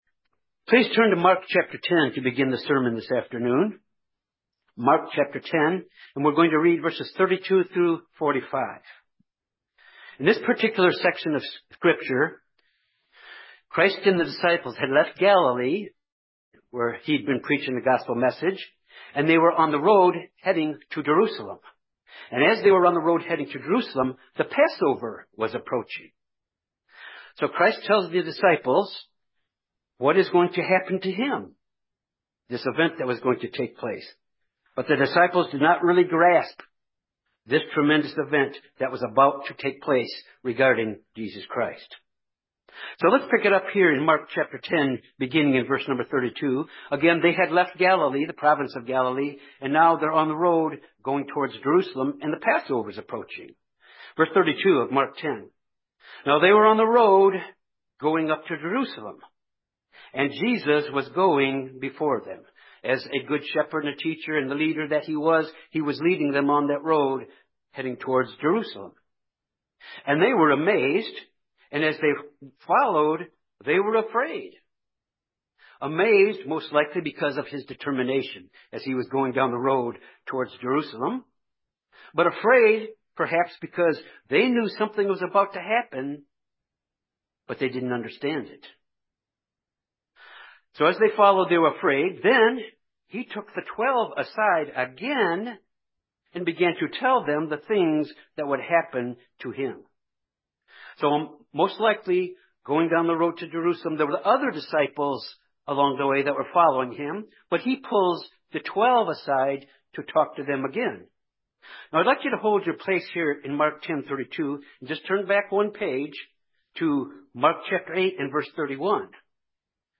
This sermon reflects upon the great ransom price paid by Christ for all of us.